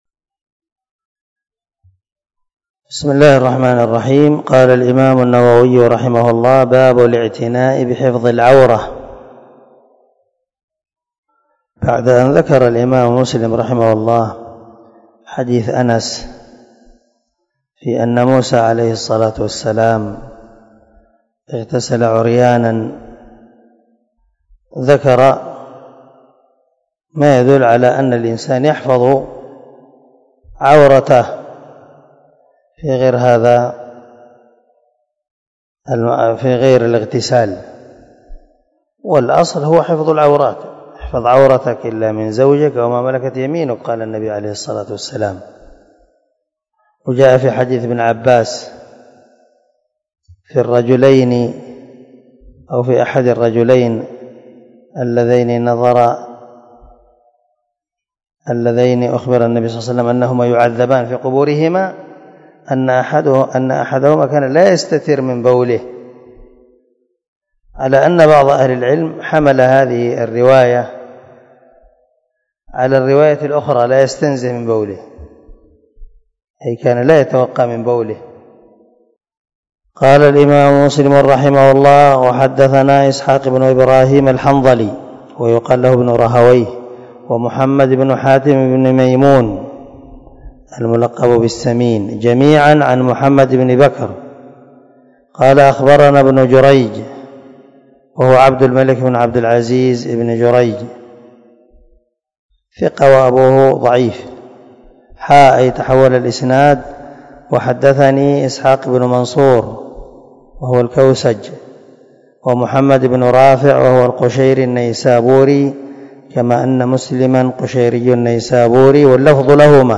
240الدرس 24 من شرح كتاب الحيض حديث رقم ( 340 - 341 ) من صحيح مسلم